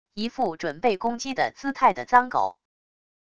一副准备攻击的姿态的脏狗wav音频